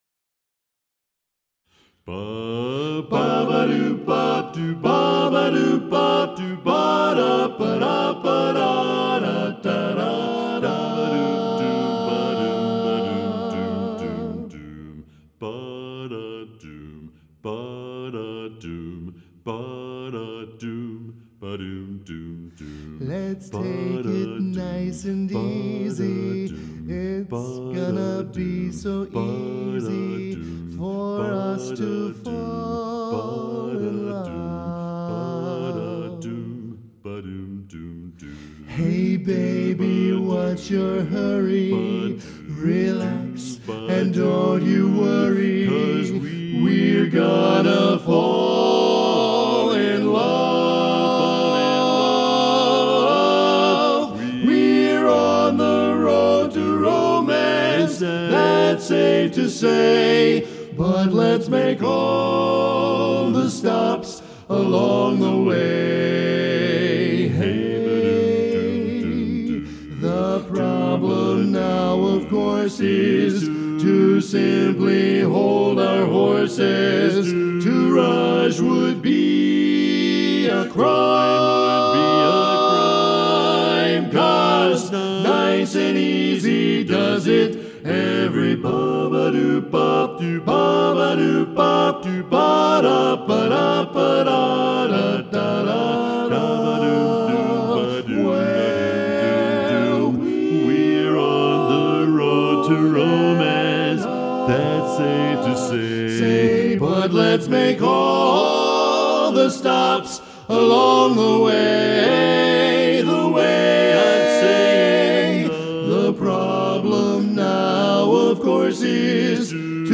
Best Barbershop Album